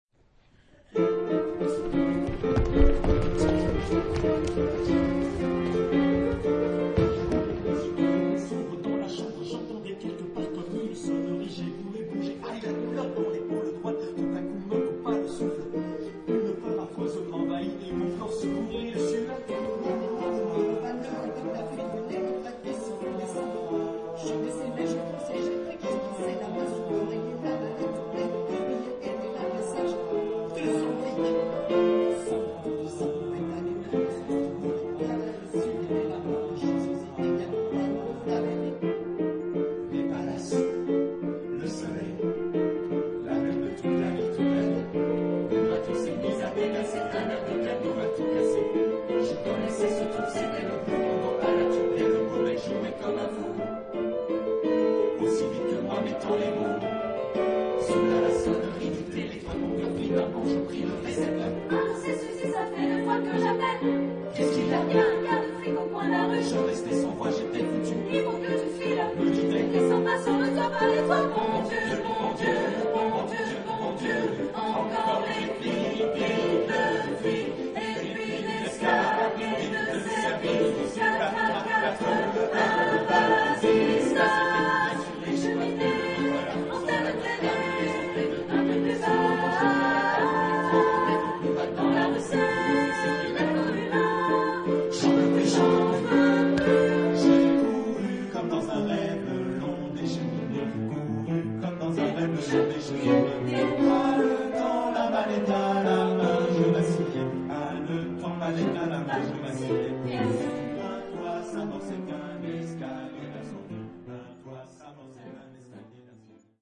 Genre-Style-Forme : Jazz choral
Caractère de la pièce : jazzy
Type de choeur : SMATBarB  (6 voix mixtes )
Instrumentation : Piano  (1 partie(s) instrumentale(s))